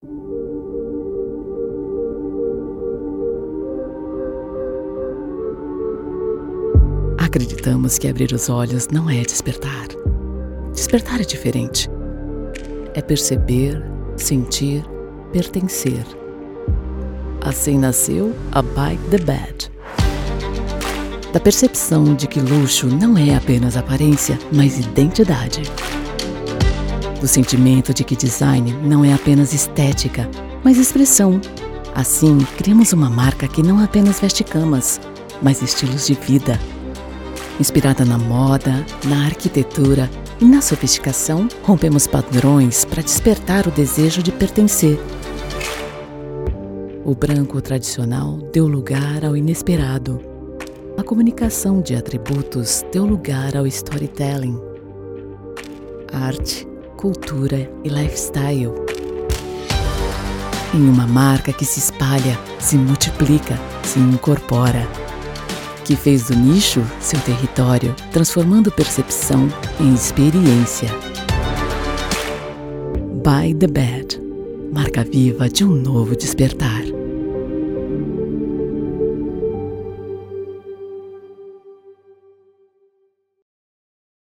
Friendly, Natural, Reliable, Versatile, Corporate
Explainer